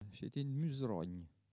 Localisation Notre-Dame-de-Monts
Catégorie Locution